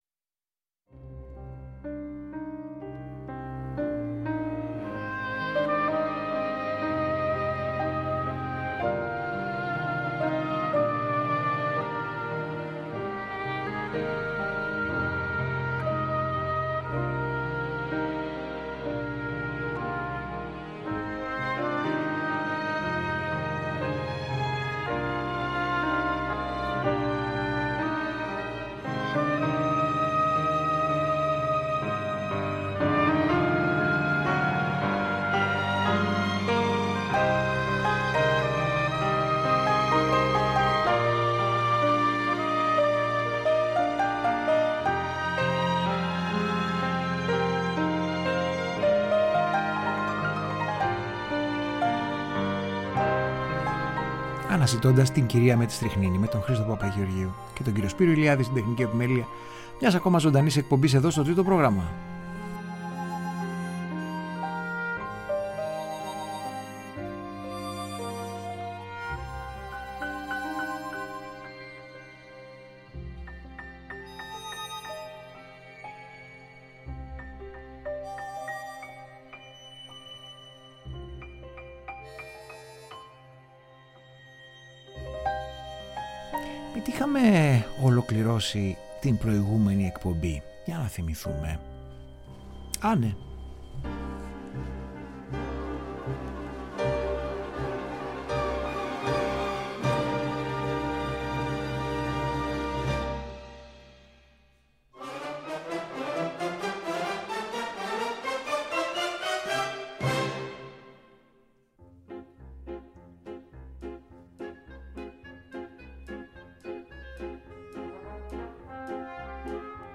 SOUNDTRACKS Κινηματογραφικη Μουσικη